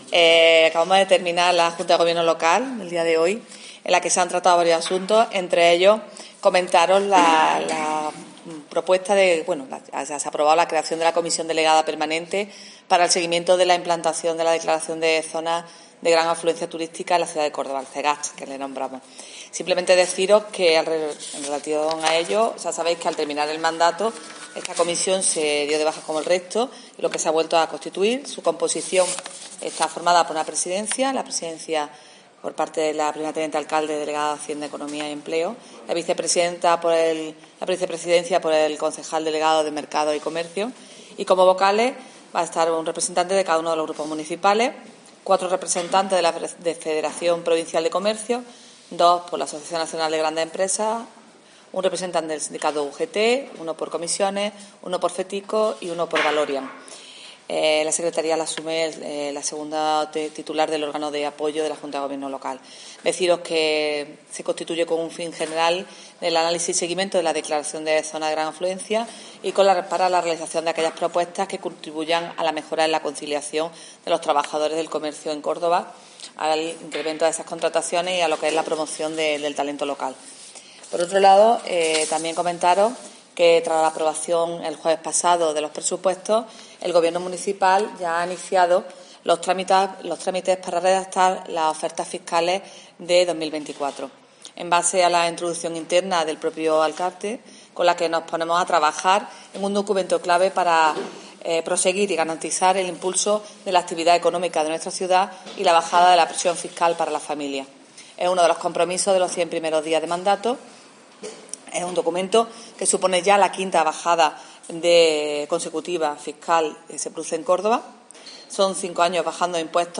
En rueda de prensa, Torrent ha explicado que dichos trámites han comenzado en base a la instrucción del propio alcalde, el popular José María Bellido, y "en consonancia con el plan iniciado por el Gobierno municipal desde el año 2019 sobre la adecuación de la presión fiscal de los tributos municipales para igualarla e incluso disminuirla respecto a la media nacional".